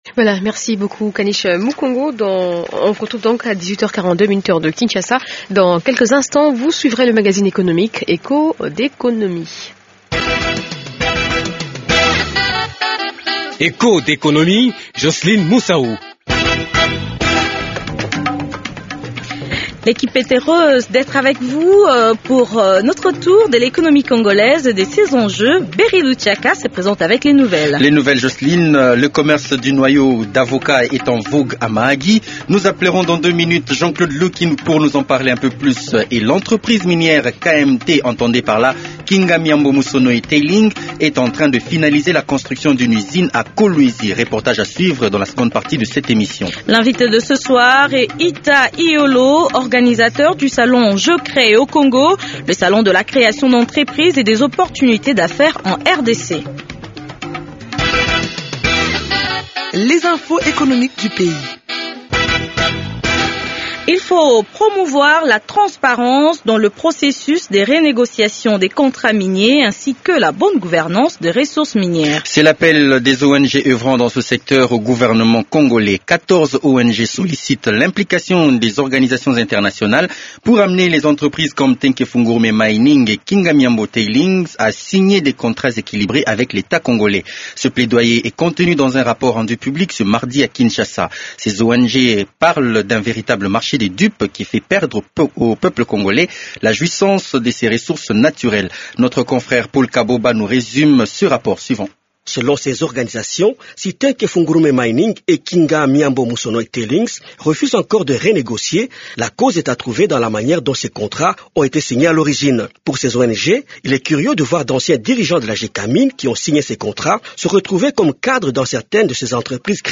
A suivre aussi, le reportage sur la construction d’une usine à Kolwezi.